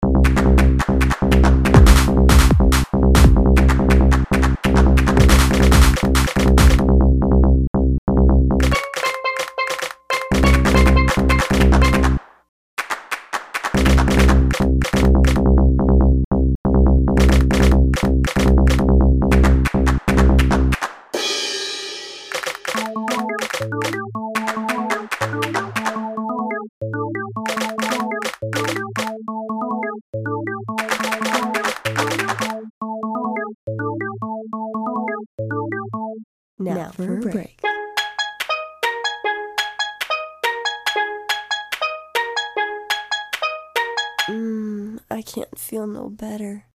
Oldschool Dance Klassiker